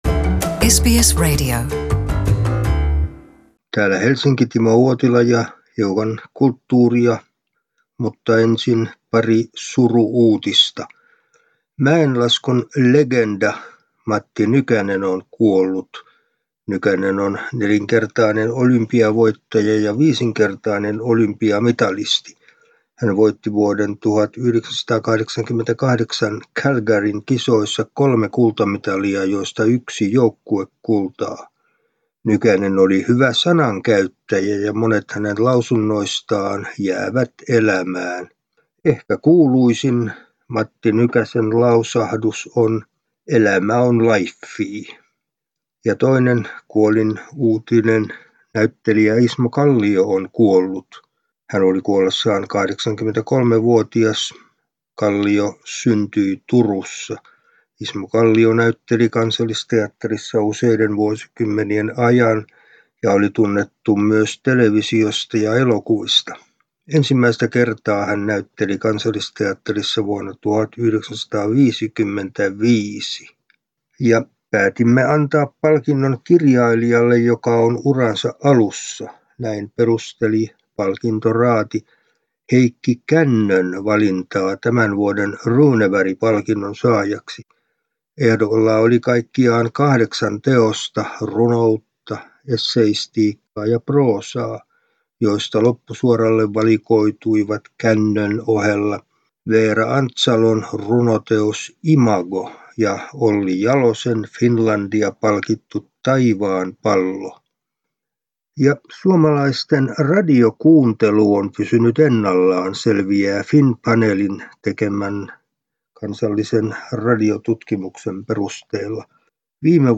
kulttuuriraportti